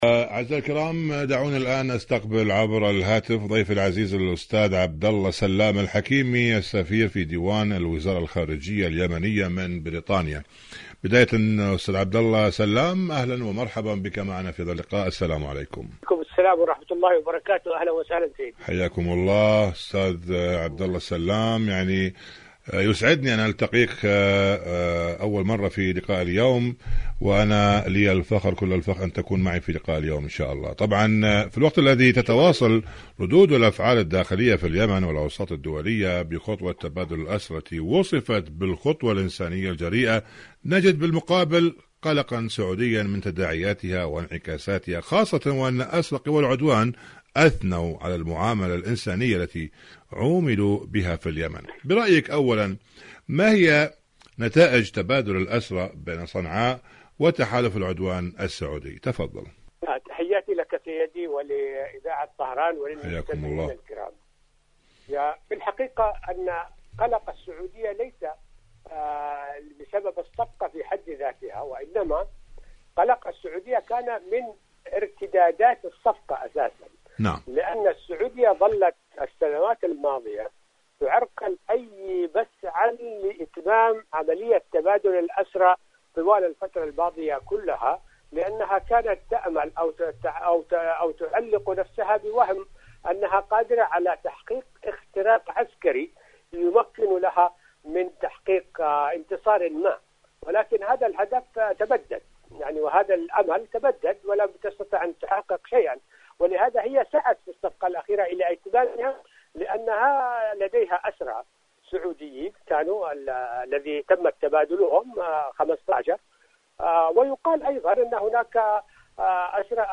إذاعة طهران-اليمن التصدي والتحدي: مقابلة إذاعية مع عبدالله سلام الحكيمي السفير في ديوان الوزارة الخارجية اليمنية من بريطانيا حول موضوع قلق سعودي رغم نجاح خطوة تبادل الأسرى.